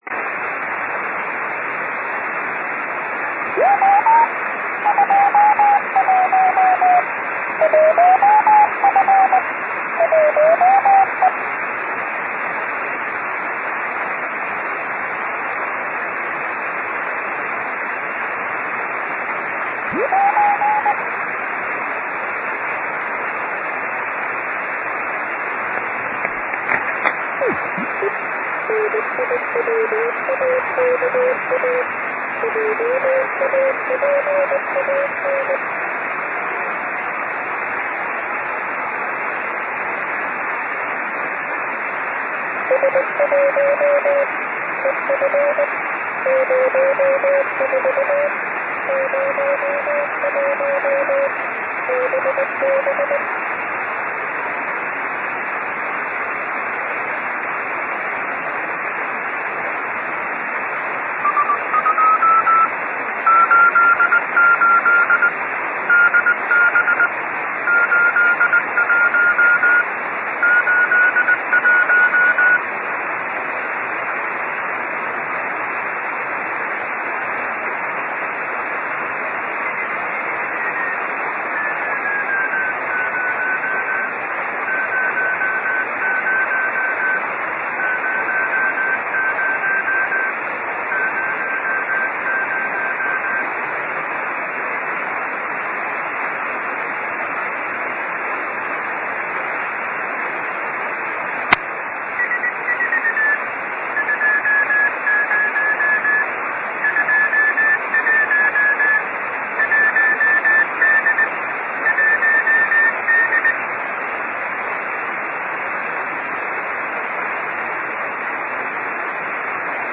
Ascoltate , al momento, la telemetria CW su 437.250 Mhz SSB.
Questo e' il files AUDIO formato MP3, registrazione dei segnali radio captati, l' 8 Ottobre 2012 orbita serale (ISS) delle 21:50, antenna TURNSTILE rx Yaesu FT-817.